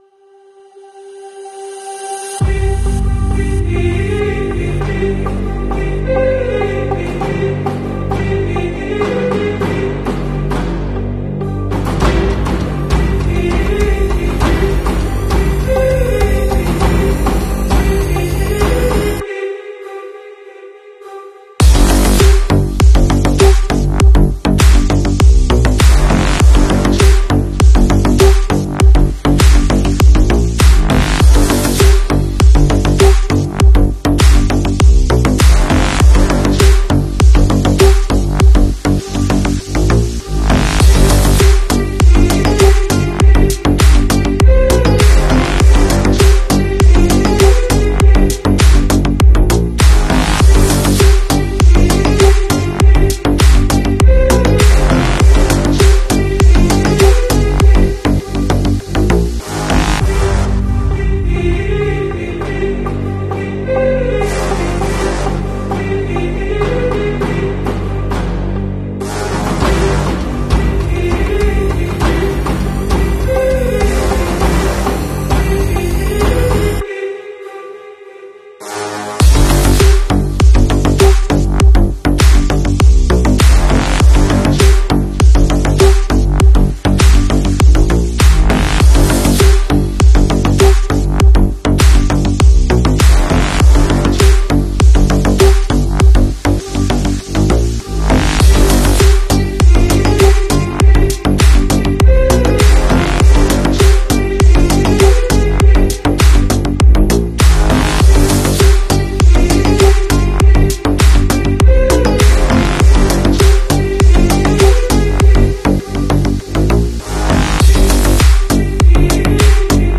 Siren Saund Circuit Making Sound Effects Free Download